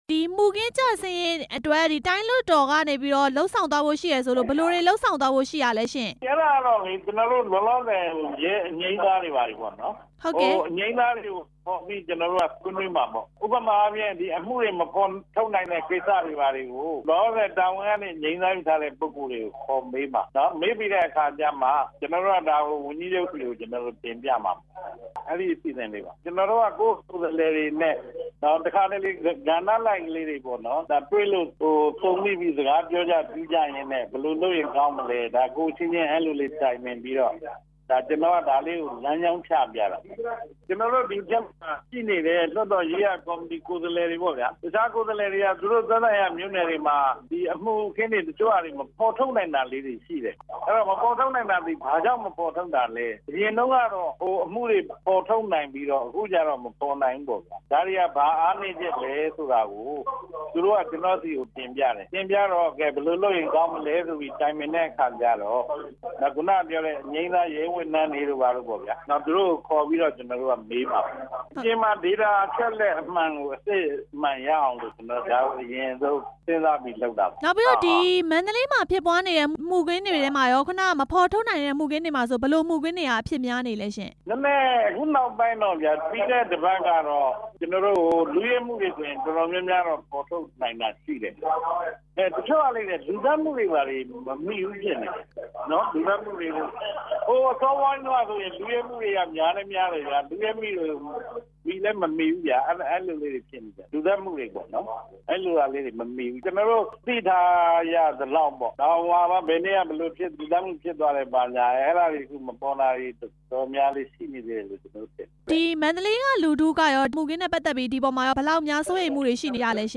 မန္တလေးတိုင်း လွှတ်တော် ဥက္ကဌ ဦးအောင်ကျော်ဦးနဲ့ မေးမြန်းချက်